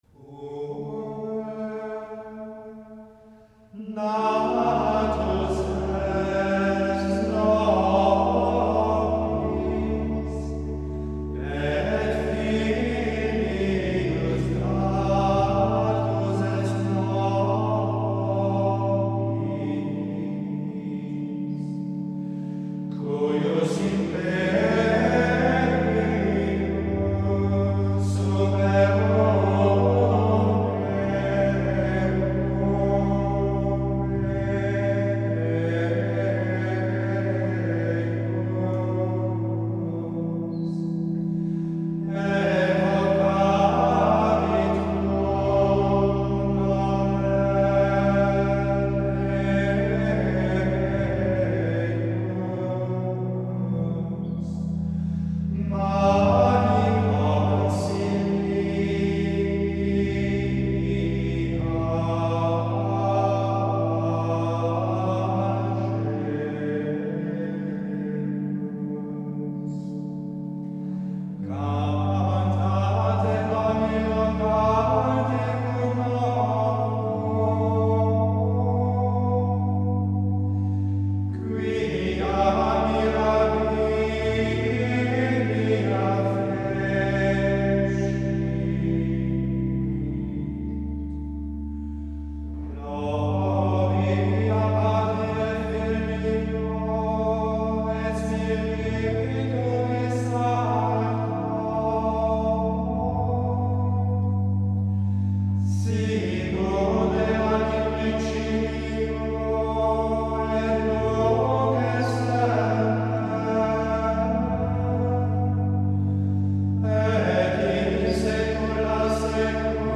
4. El canto gregoriano
Se entiende comúnmente por canto gregoriano un género de música a una sola voz, de tonalidad diatónica en general y ritmo libre.
031-puer-natus-est-introito-.mp3